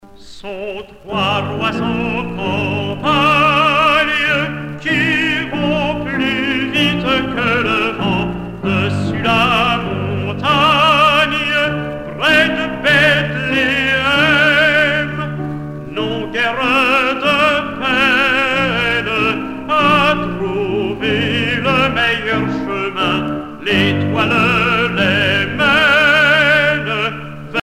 circonstance : Noël, Nativité
Pièce musicale éditée